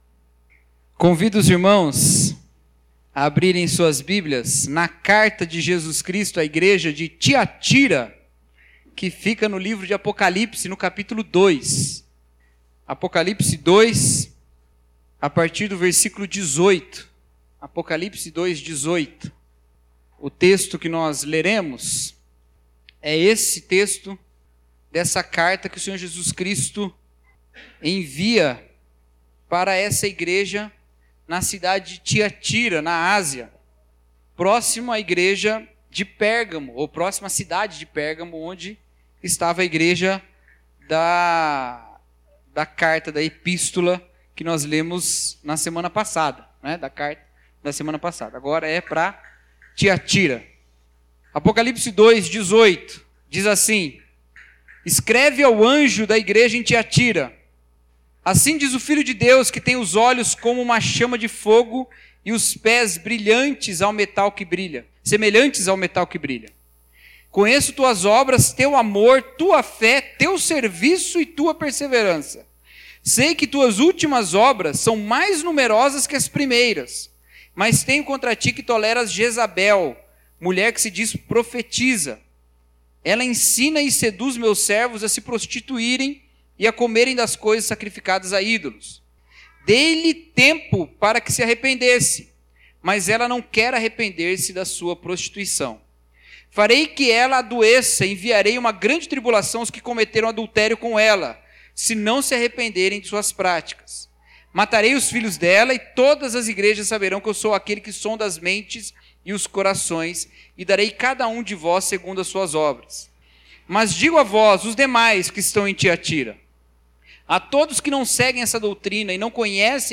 Mensagem: Uma Igreja Que Julga a Profecia